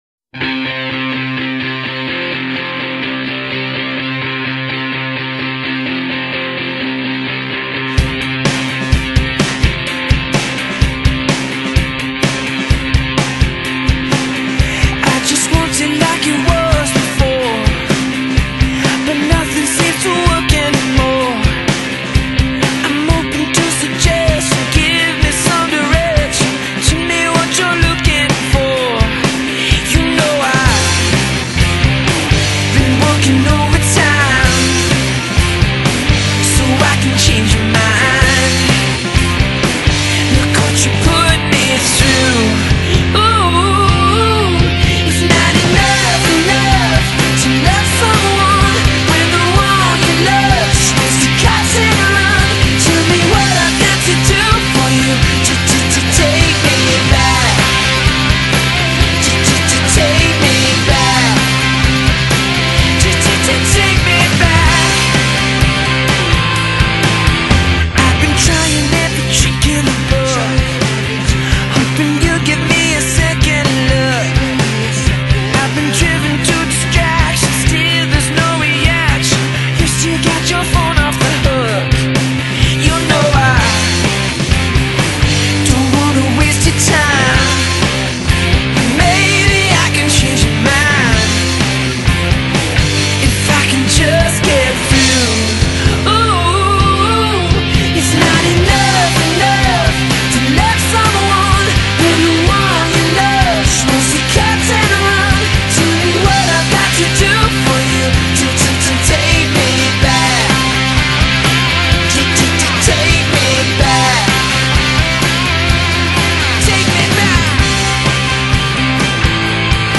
straight-up, guitar-driven poprock group
with some very catchy hooks in the chorus